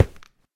step
stone5.ogg